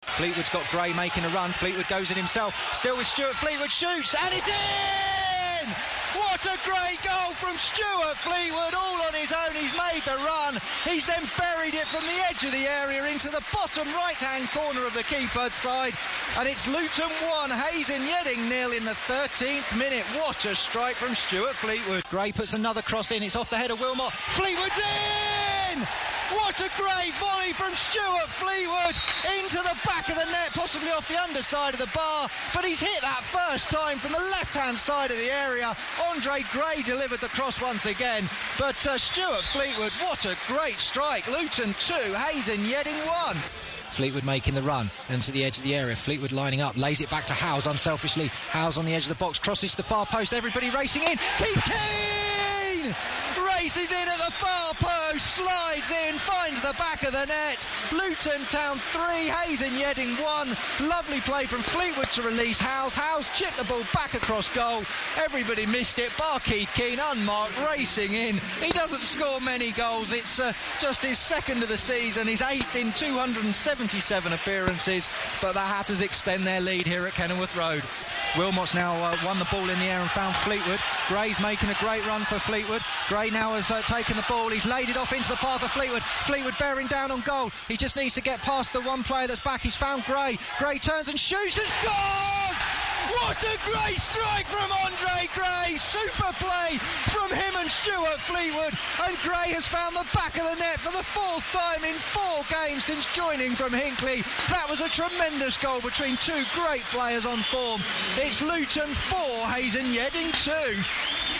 Stuart Fleetwood (twice!), Keith Keane and Andre Gray (4 in 4!) all scored for the Hatters on Easter Monday. Here's the commentary of the goals as heard on Diverse FM.